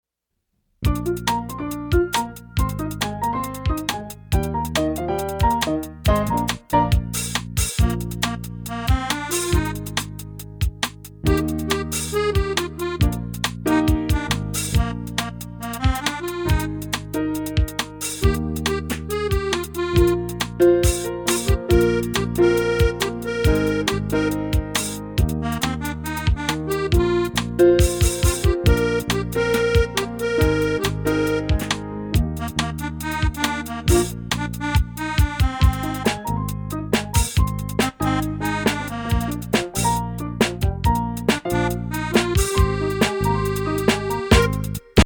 *  Catchy melodies, dumb jokes, interesting stories